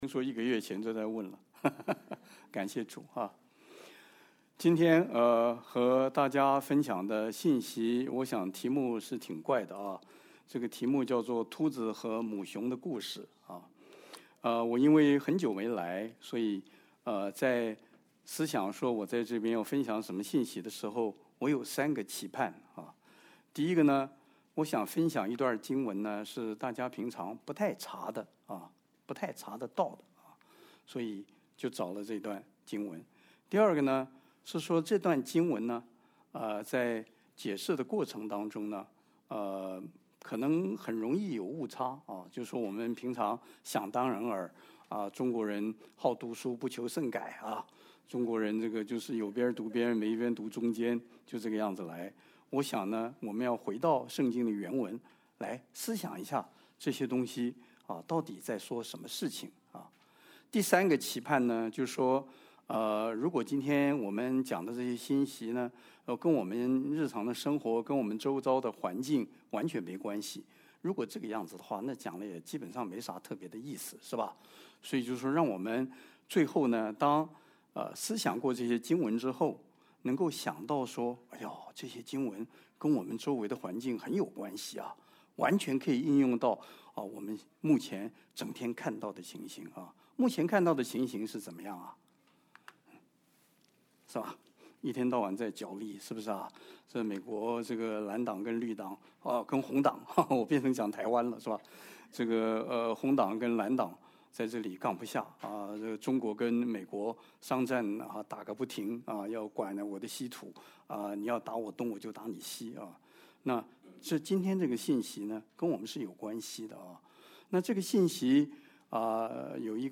主日证道 Download Files Notes « 生命發光一切所需 乐意奉献的心 » Submit a Comment Cancel reply Your email address will not be published.